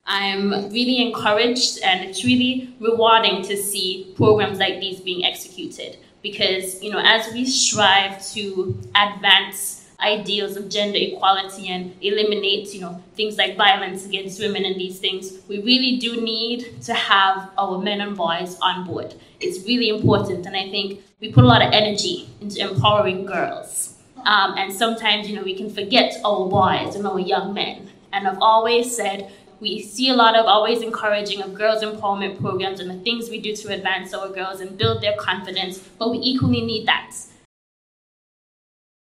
Junior Minister of Gender Affairs in the Federal Government, Hon. Isalean Phillip officially launched Nevis’ Boys Mentorship Program on Tuesday, October 18th, at the Malcolm Guishard Recreational Park on Nevis.
Minister Phillip had this to say: